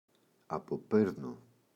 αποπαίρνω [apo’perno]